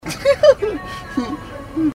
Laugh 10
Category: Comedians   Right: Both Personal and Commercial